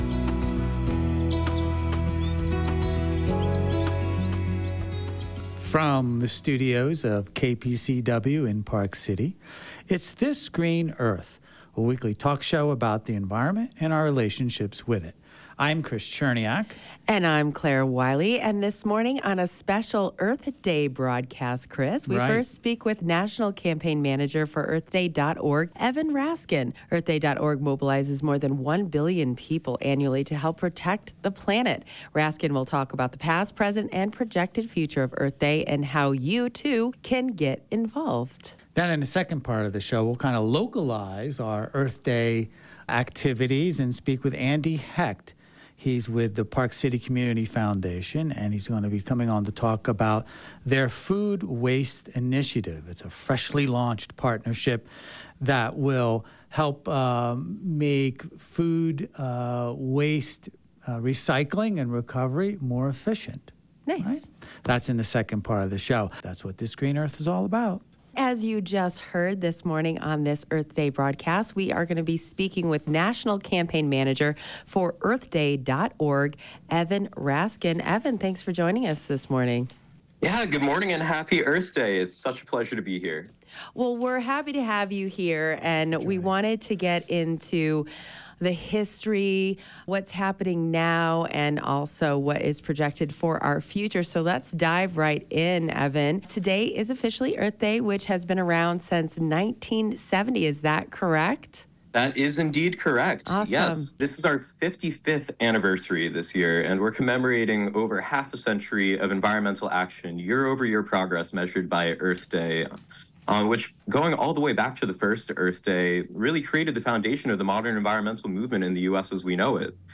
In the 26th Patreon bonus podcast you join me for a very special Halloween Patreon episode as I am on location in the Scottish Borders.